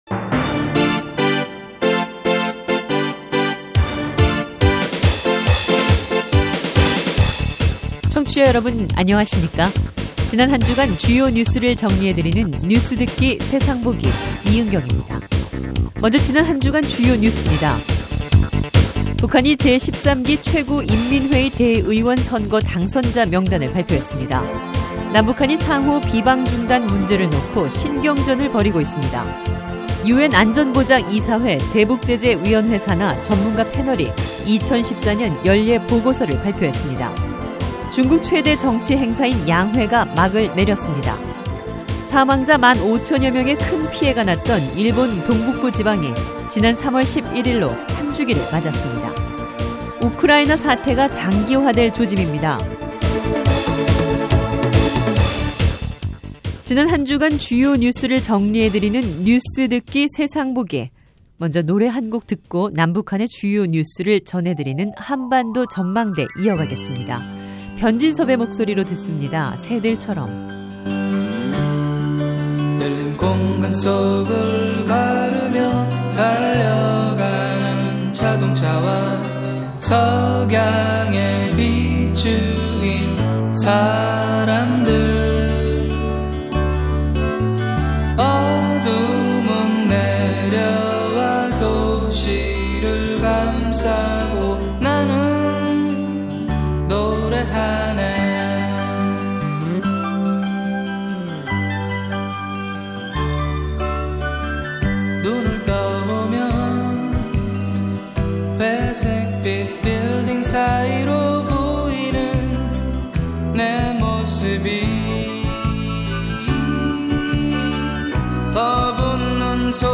뉴스해설: 남북한 비방 중단 신경전, 우크라이나 사태